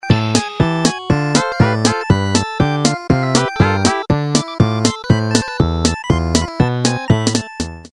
Siemens полифония. Шансон